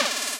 描述：单声道录音，由硬币在金属托盘上翻转而产生的短暂效果，最后音调升高
标签： 效果 声音